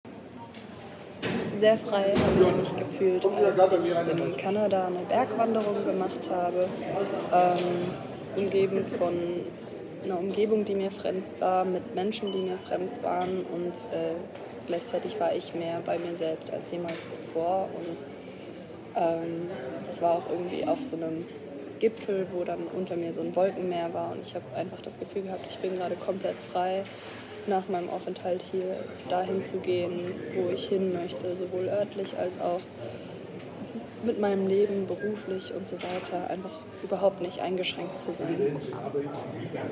Standort der Erzählbox:
Theaterversammlung im Staatstheater Cottbus @ Cottbus